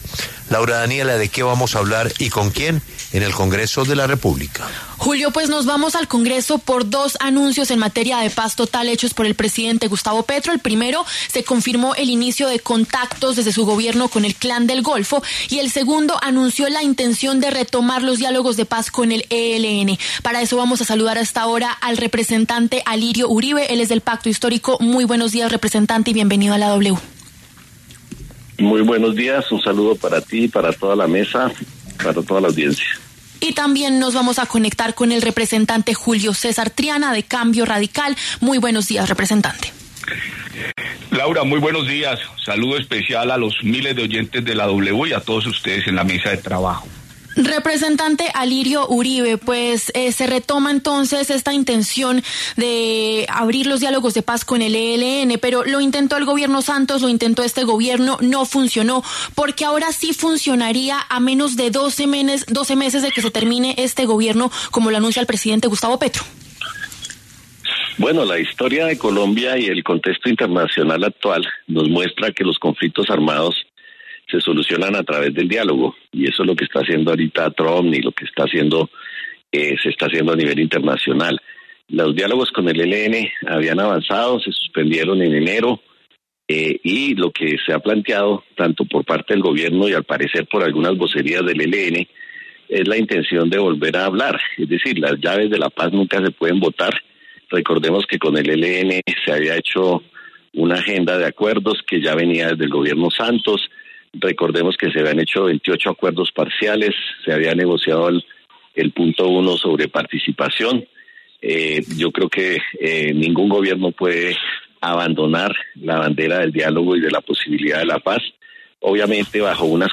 Debate: ELN, ‘Pipe Tuluá’ y Tren de Aragua piden pista en la paz total del Gobierno Petro
Los representantes del Pacto Histórico, Alirio Uribe, y de Cambio Radical, Julio César Triana, pasaron por los micrófonos de La W.